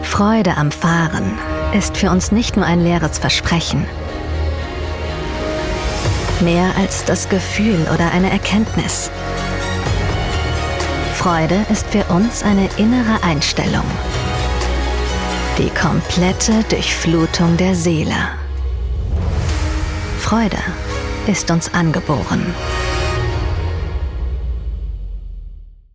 dunkel, sonor, souverän, markant, sehr variabel
Werbung Auto gefühlvoll
Commercial (Werbung)